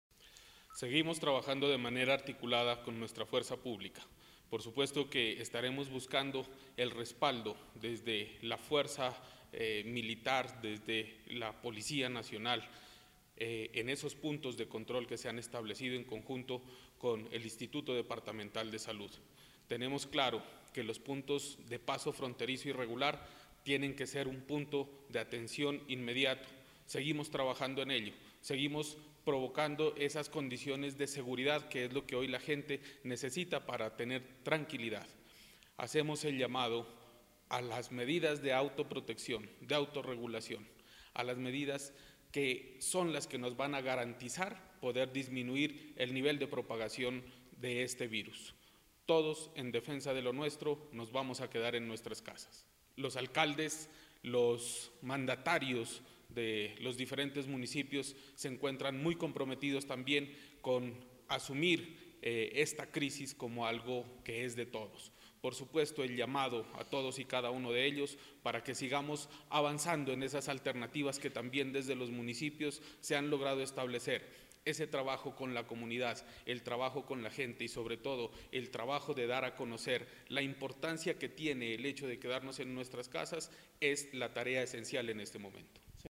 El Secretario de Gobierno de Nariño, Francisco Cerón, a su vez manifestó que: “En coordinación con la Policía y el Ejército Nacional, se continúa la vigilancia de las fronteras y el apoyo en los puntos de control, establecidos por el Instituto Departamental de Salud de Nariño” y añadió, que desde el Gobierno Departamental se trabaja mancomunadamente con los alcaldes de los 64 municipios, para fortalecer la medida de aislamiento preventivo como la única estrategia eficaz para evitar la propagación del virus en Nariño.
Sec-gob-Francisco-Ceron-3.mp3